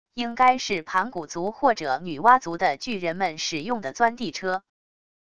应该是盘古族或者女娲族的巨人们使用的钻地车wav音频